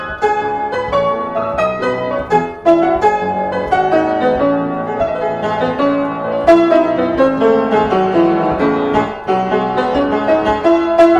Distortion on the audio even from lower input
My problem is that when I’m recording the piano (yes the piano is pretty much out of tune but that’s aside the point :stuck_out_tongue: ) and the problem I’m getting is that I’m having distortions in my audio and this is even putting the microphone farther away from the source (piano) and using the -10dB setting.
The piano I’m recording on is an upright piano I have at home and I did it just to test the audio quality of my new USB mic.
This is an overload condition, but chances are it’s not caused by the digital side or the digital converter because there are no overload red bars in Audacity.